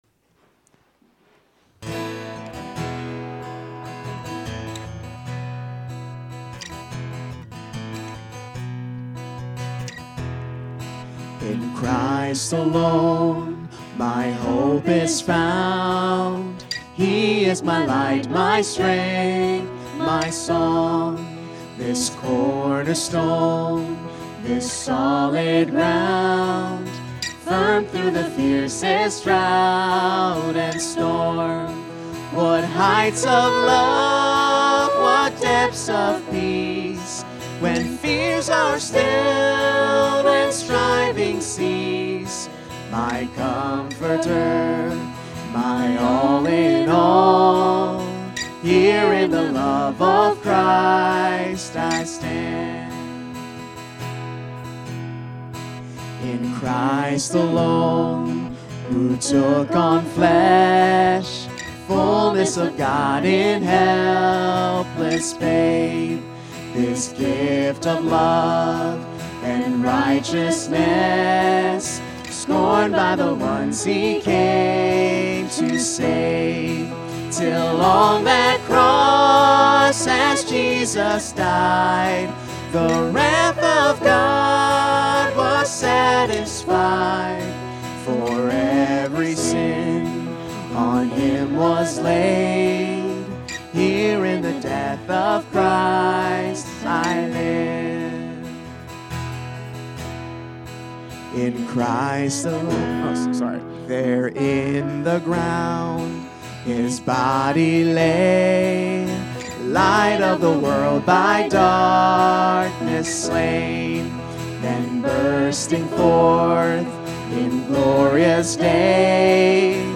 In Christ- Light Preacher